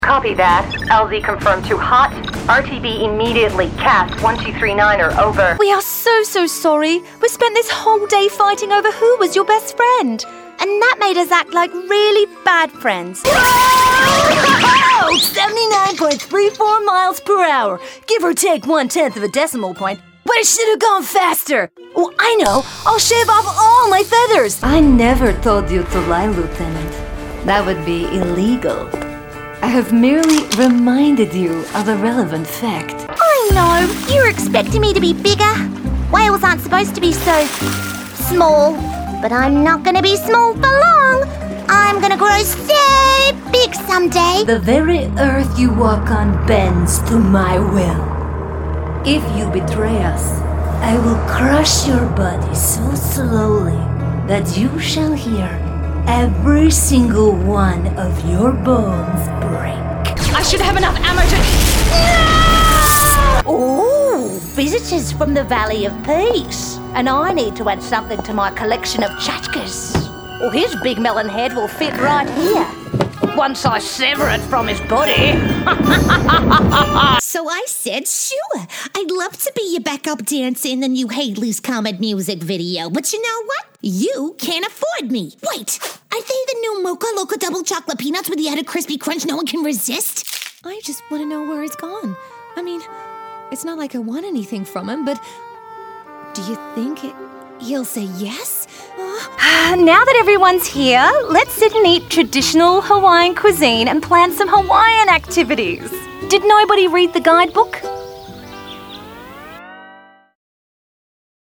English (Australia)
Commercial, Playful, Versatile